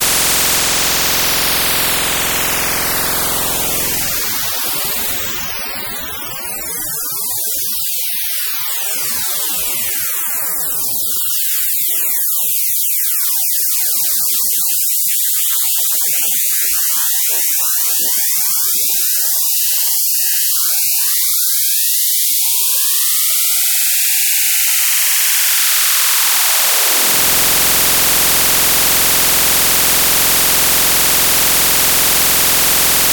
Plot Sonification